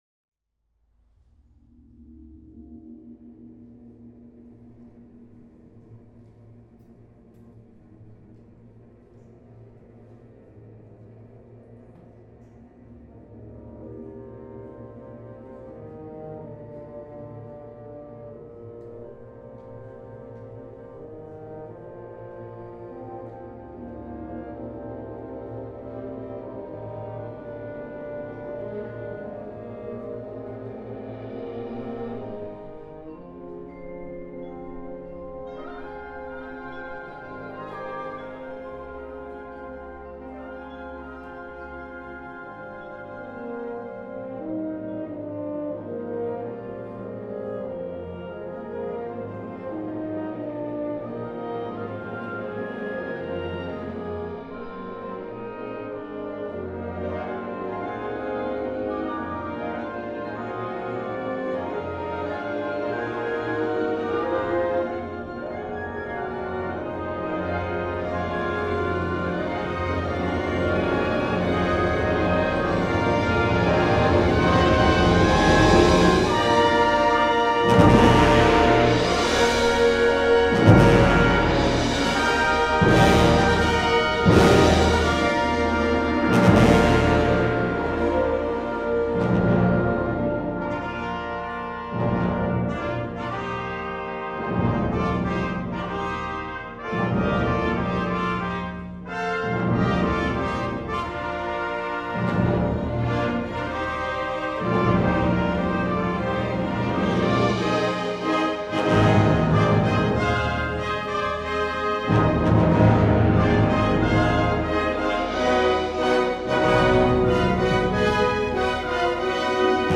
the orchestra sounded marvelous